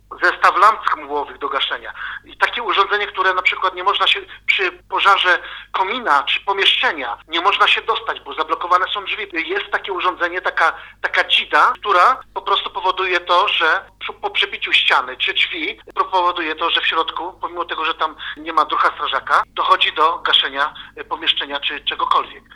– Komentuje jeden ze sprzętów Andrzej Salwa, Burmistrz Mieszkowic.